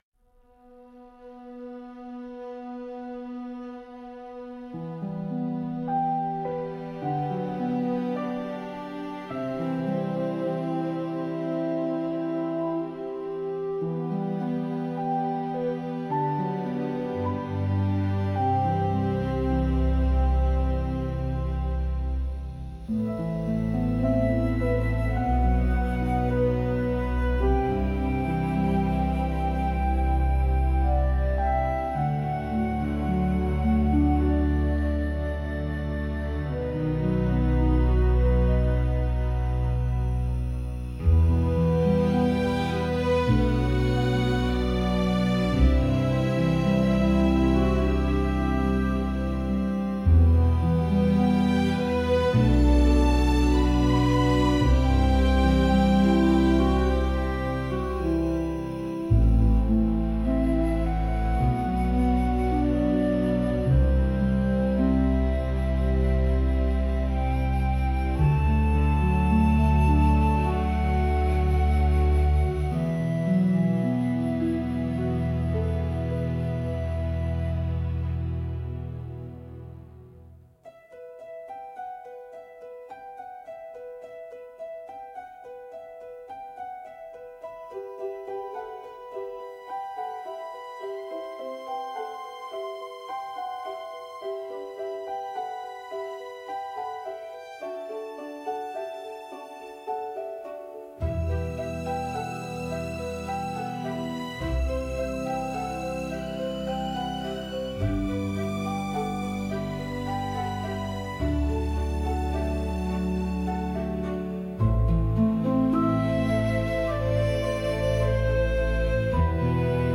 「オーケストラ」